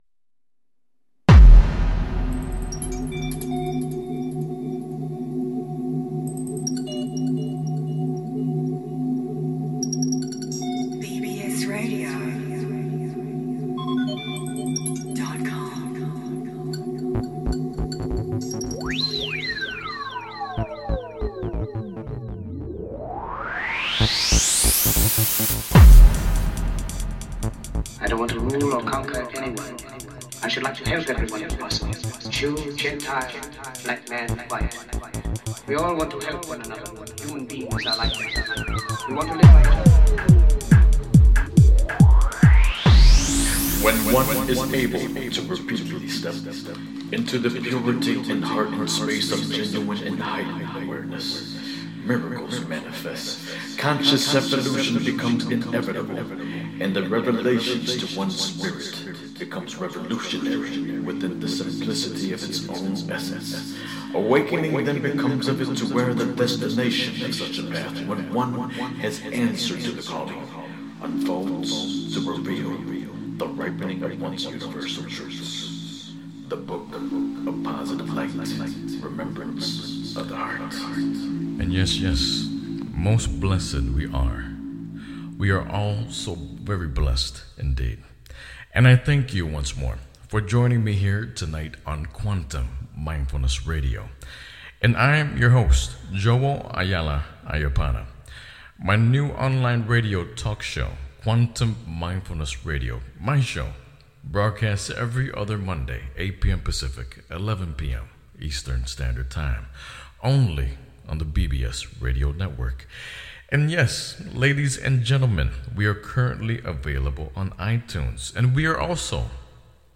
Headlined Show, Quantum Mindfulness Radio May 26, 2014